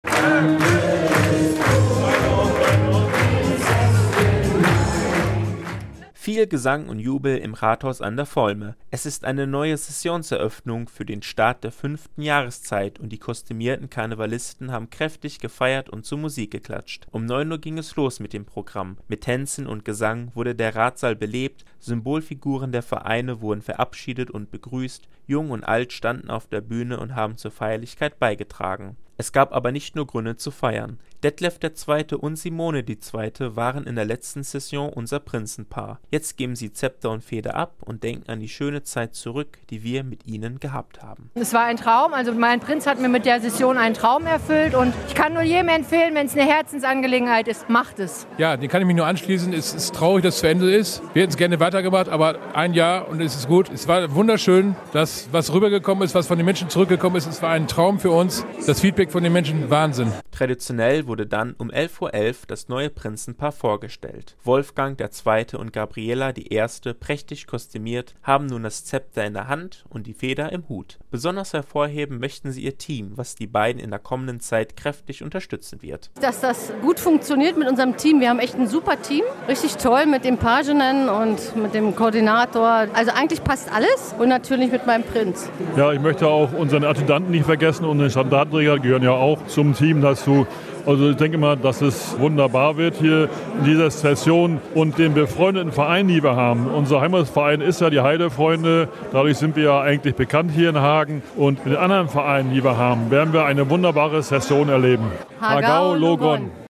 Viel Gesang und Jubel im Rathaus and der Volme. Es ist eine neue Sessionseröffnung für den Start der fünften Jahreszeit.